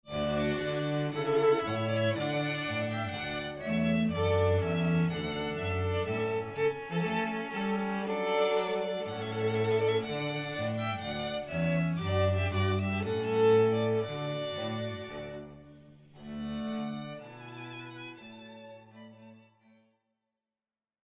A highly professional string quartet, available to hire for drinks receptions, wedding ceremonies and background music.
String Ensembles For Hire | Duo, Trio or Quartet